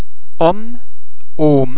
The French [ o ] sound can also be almost as open as the vowel sound in English words like otter, lot.
o_homme.mp3